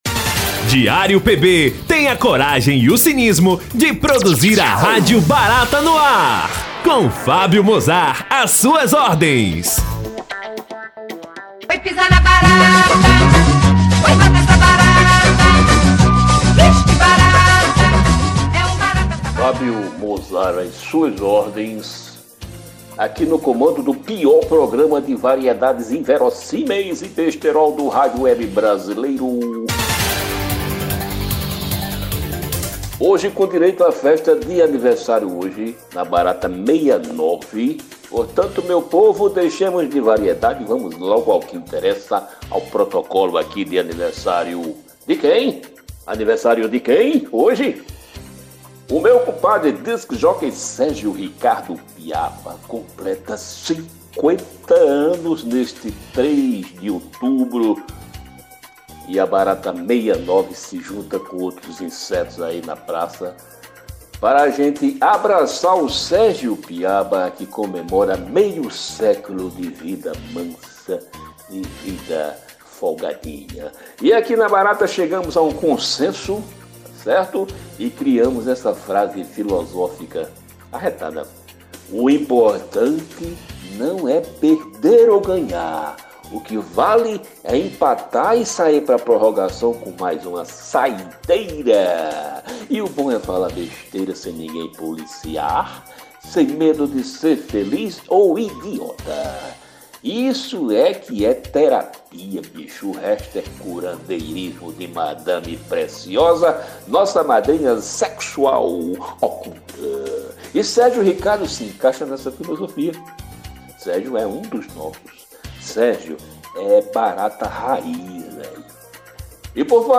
O objetivo do programa é abordar assuntos do cotidiano, comentar de maneira bem humorada, os acontecimentos da semana, de forma irreverente e leve, sempre com boas piadas.